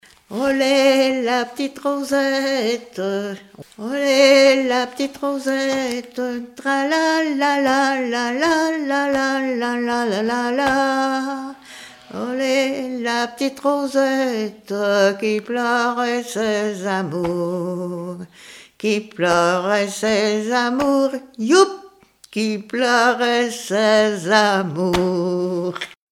Genre laisse
Témoignages, chansons de variété et traditionnelles
Pièce musicale inédite